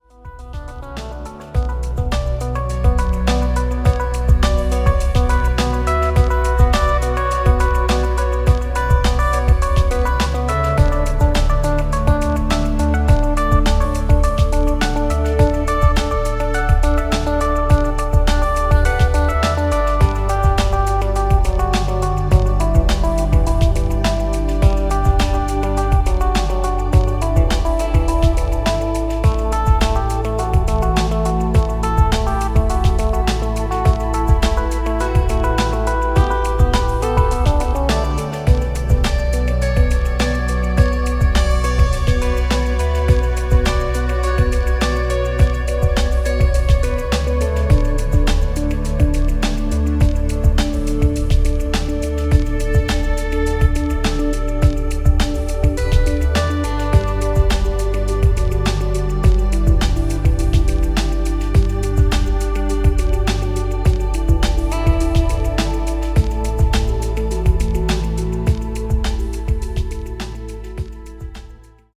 The file is treated to some M/S processing.